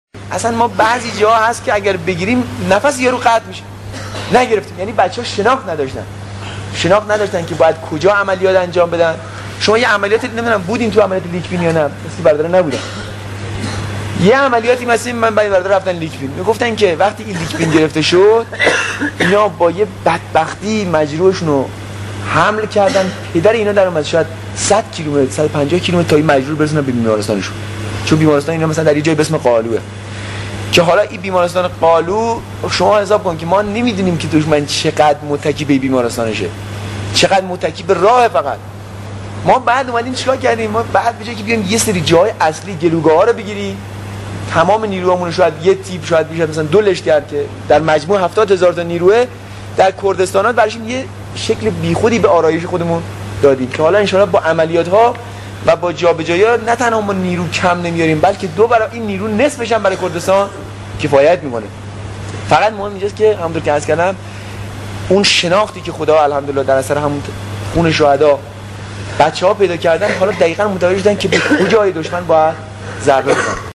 صوت شهید محمد بروجردی و سخن ایشان در جمع نیرو های کردستان و اشاره به شناخت نوع عملیات و تکنیک ها